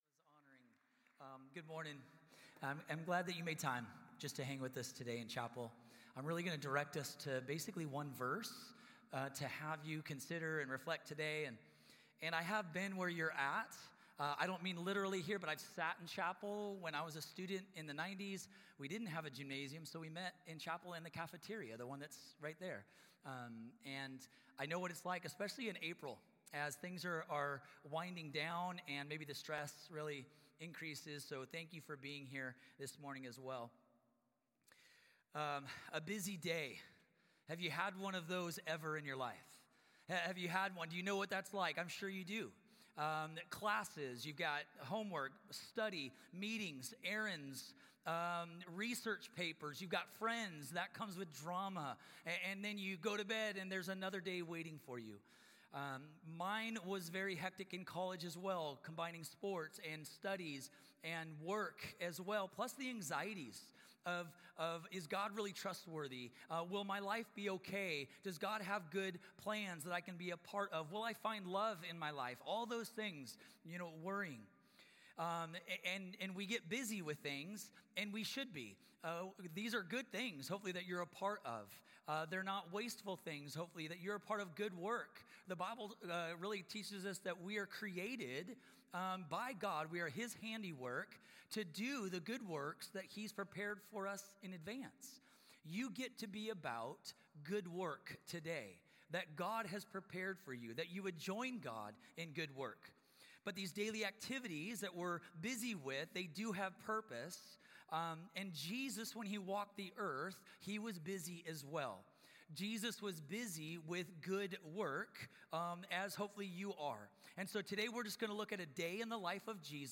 This talk was given in chapel on Friday, April 4th, 2025.